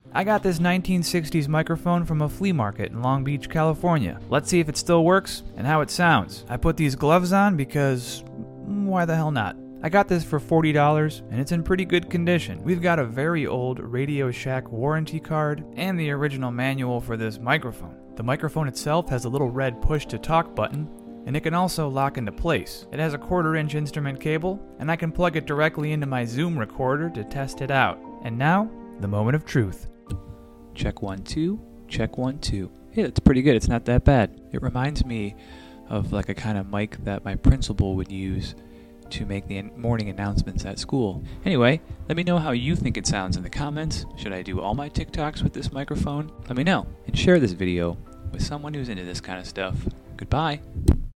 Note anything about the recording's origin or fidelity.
Testing an old 1960s microphone sound effects free download